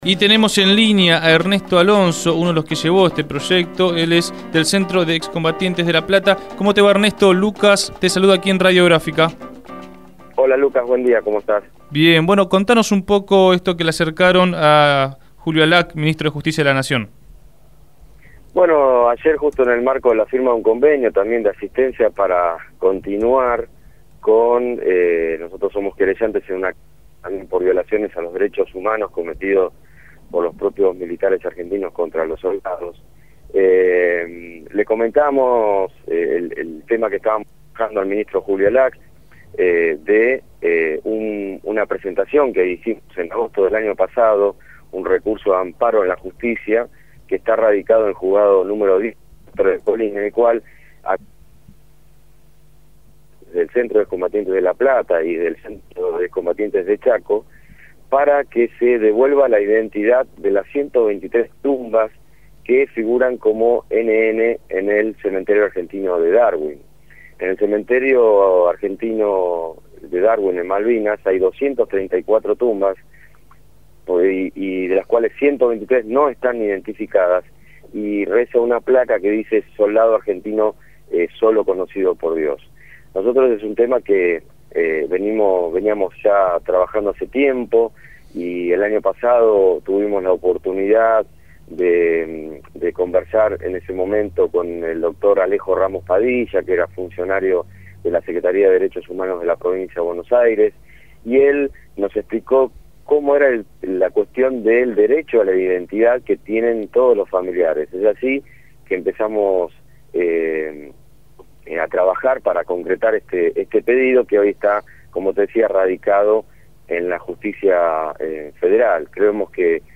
fue entrevistado en Punto de Partida.